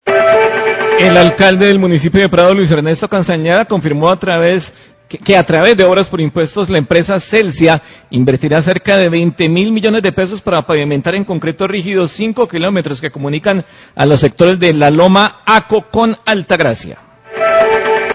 Radio
Titular-El Alcalde de Prado, Luis Ernesto Castañeda, confirma la inversión de cerca de 20 mil millones de pesos por parte de Celsia para la pavimentación de 5 kilómetros de una vía rural en este municipio.